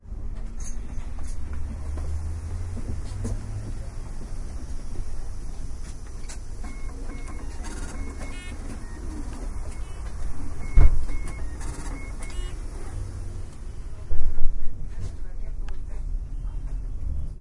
公交车 " 公交车门关闭
描述：关闭公交车门
标签： 关闭 交通 公交车 车门
声道立体声